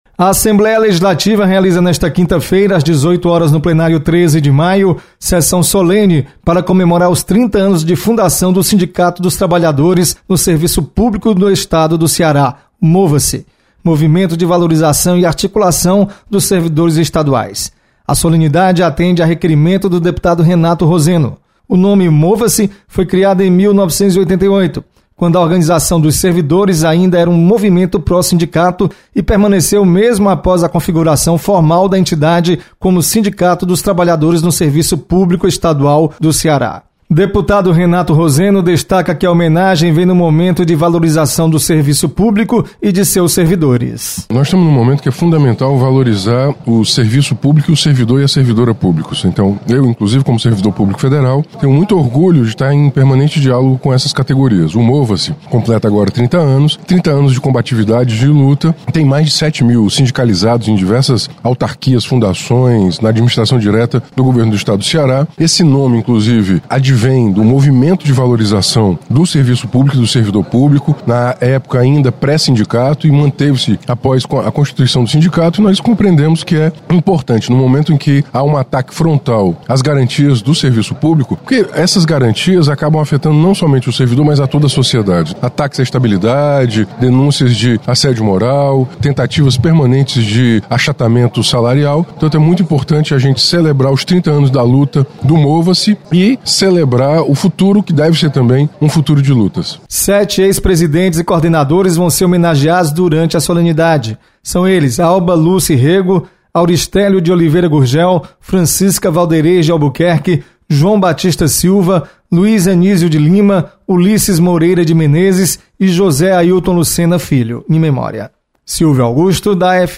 Você está aqui: Início Comunicação Rádio FM Assembleia Notícias Sessão Solene